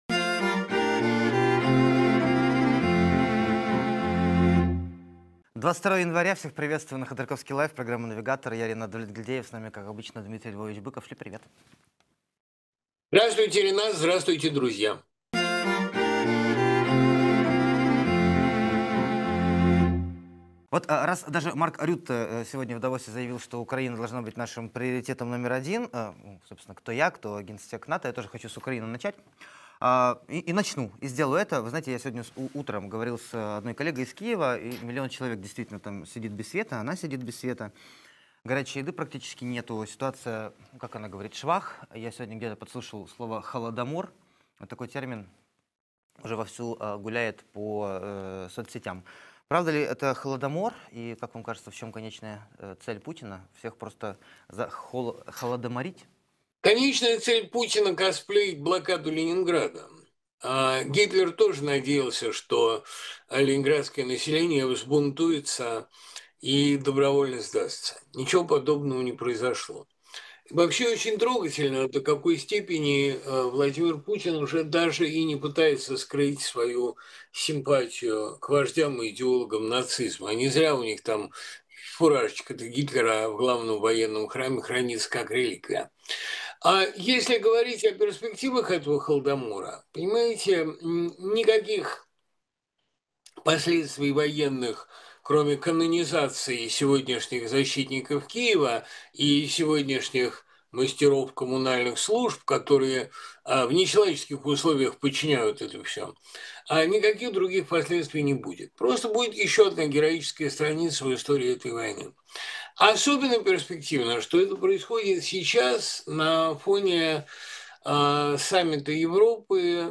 Дмитрий Быков поэт, писатель, журналист